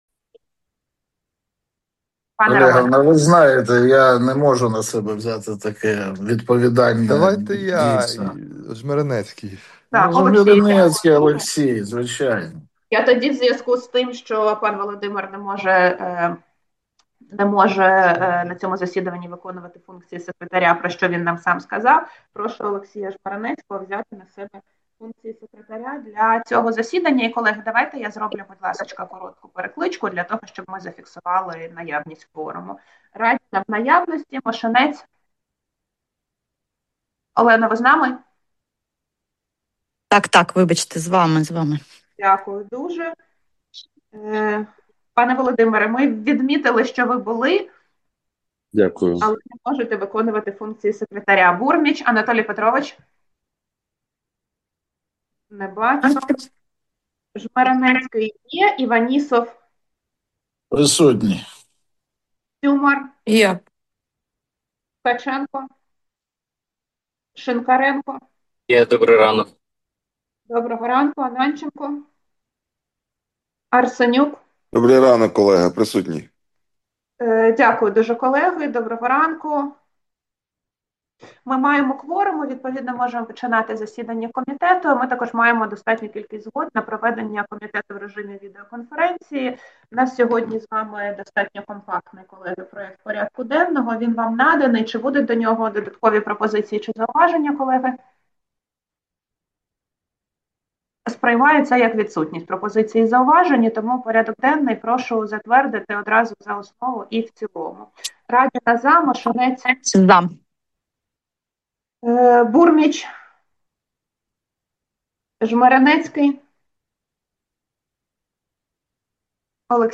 Аудіозаписи засідань Комітету за квітень - червень 2026 року